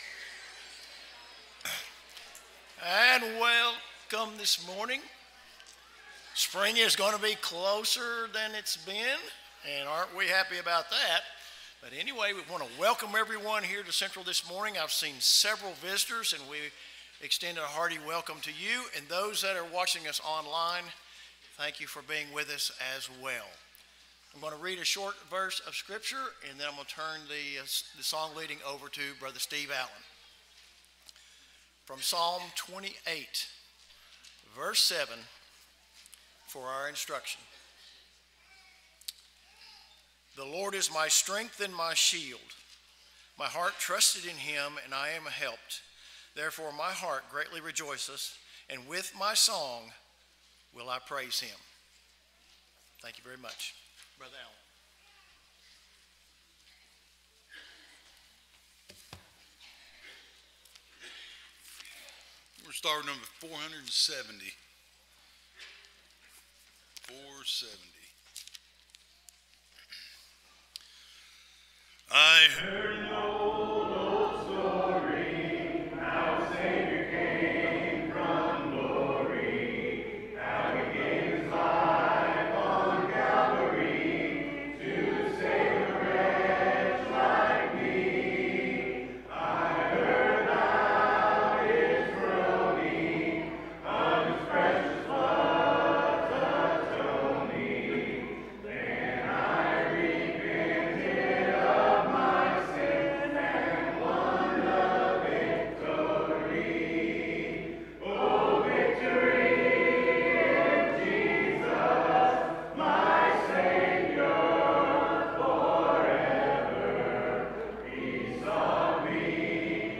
Psalm 56:3, English Standard Version Series: Sunday AM Service